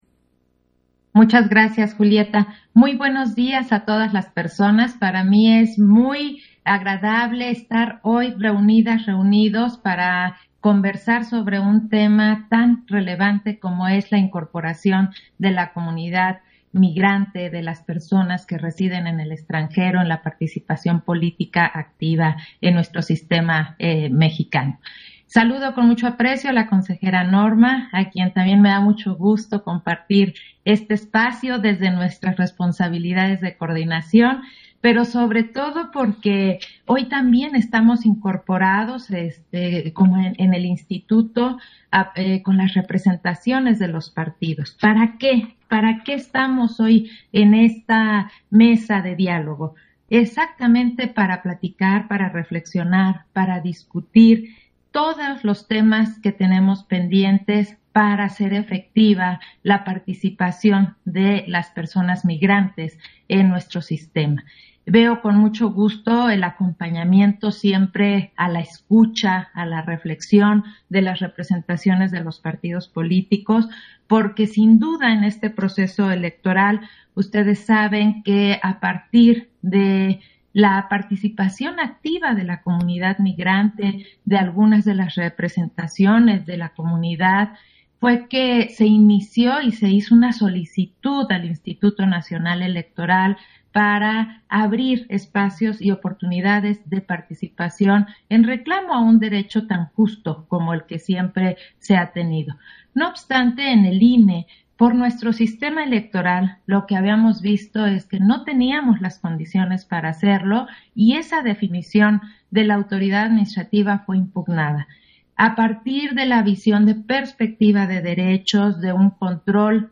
Intervención de Claudia Zavala, en la mes de diálogo, La incorporación de las personas migrantes y residentes en el extranjero en los espacios de representación política